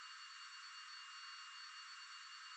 Engine B
engine-b.wav